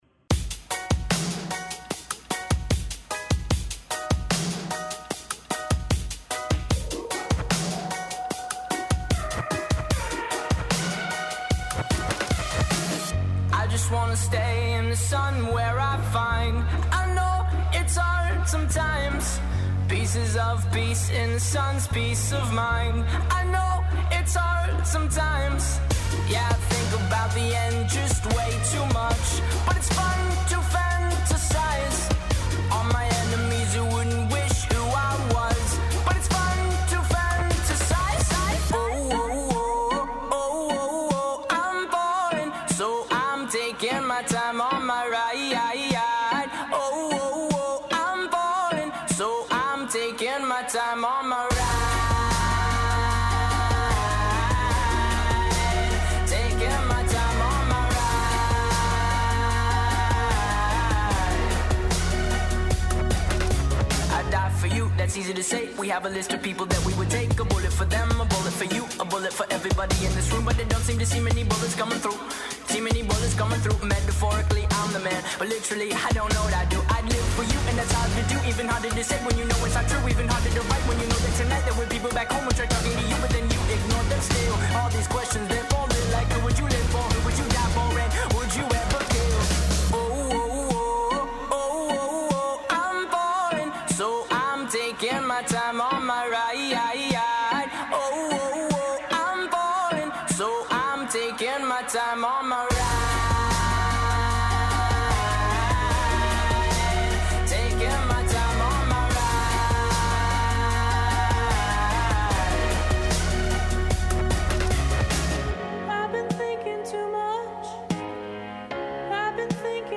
This week is the 2015 show, featuring music from that year. Broadcast every Saturday afternoon from 2 to 3:30pm on WTBR.